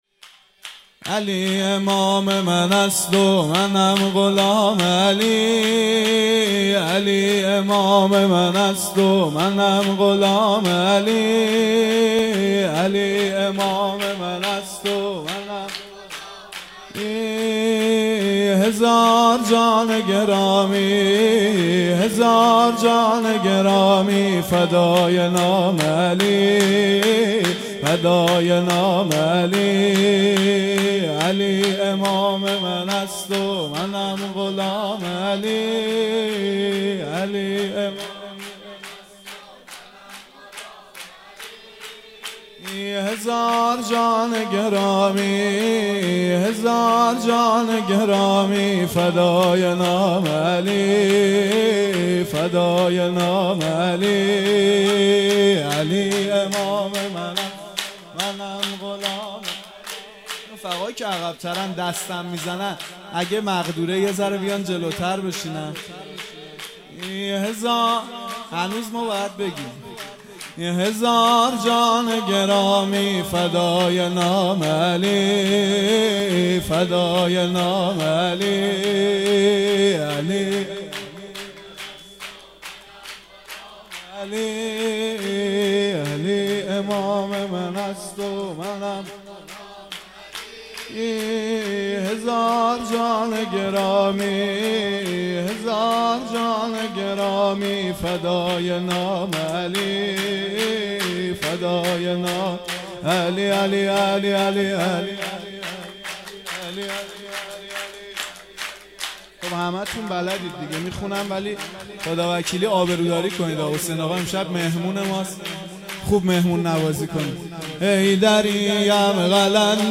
سرود| علی امام من است و منم غلام علی